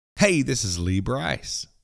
Liners